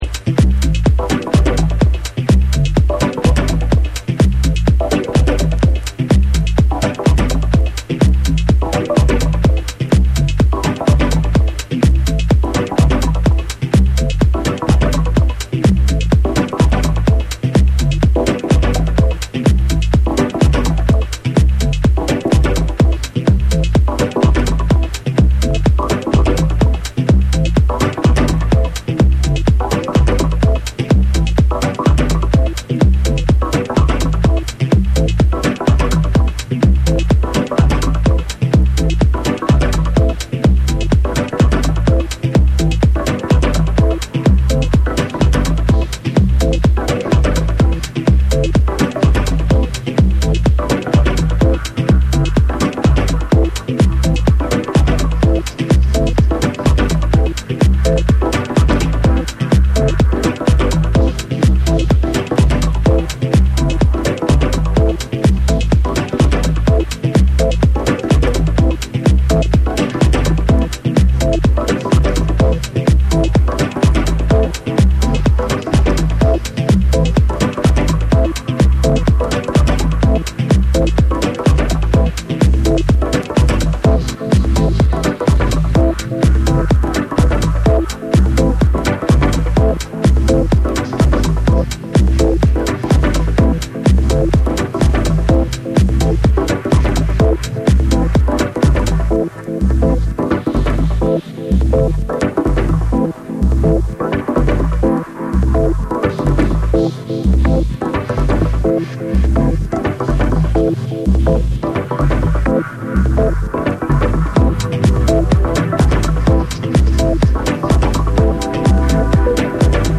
重厚感のある4/4トラックを軸にジワジワとサイケデリック空間へと引き込むダブ・テクノを収録！